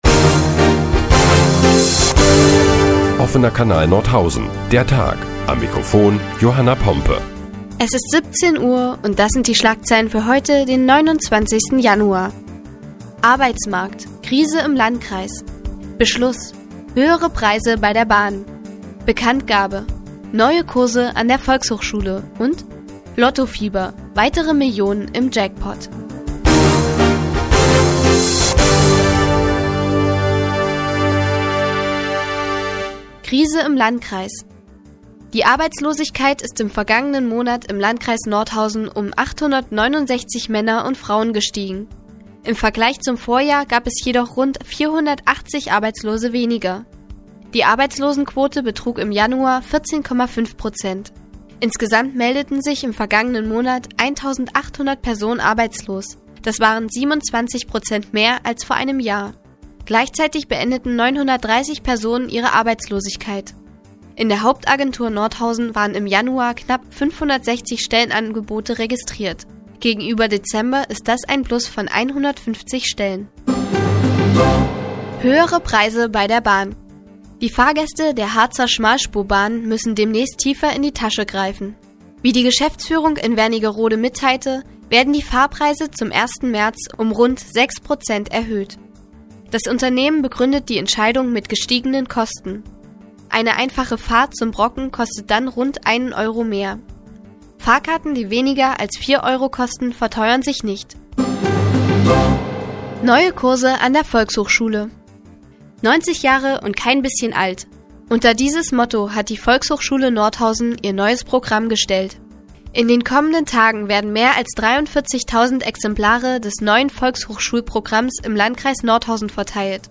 Die tägliche Nachrichtensendung des OKN ist nun auch in der nnz zu hören. Heute geht es unter anderem um die Arbeitslosenquote im Landkreis und höhere Preise bei der Bahn.